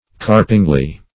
-- Carp"ing*ly , adv.
carpingly.mp3